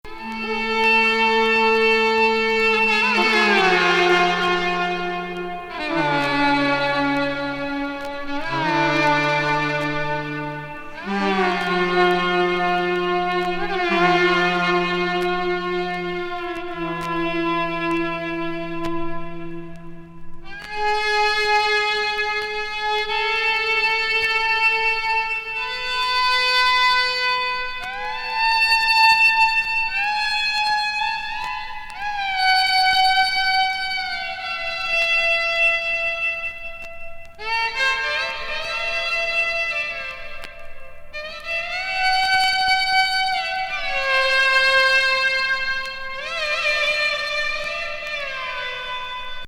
チュアルな70年作!様々な邦楽器と、声の前衛舞台音楽的な趣。